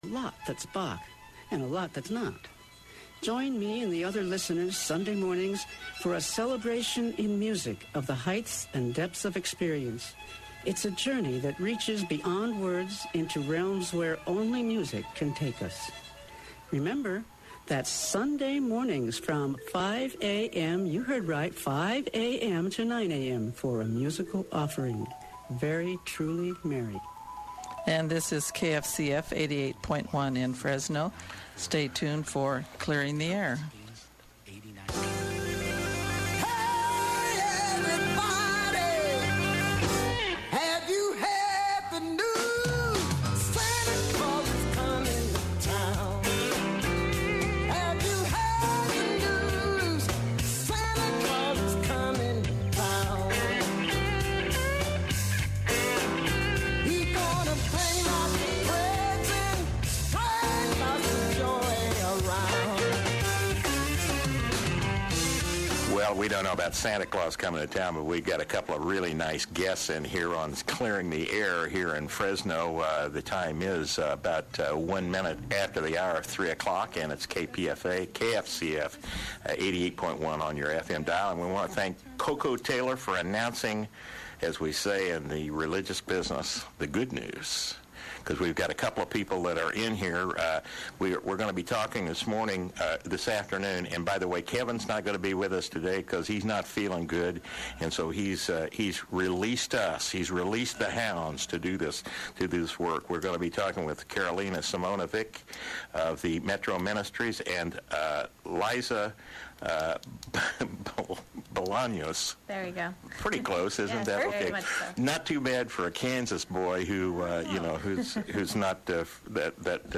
Show aired on KFCF 88.1 FM in Fresno on 12/22/06.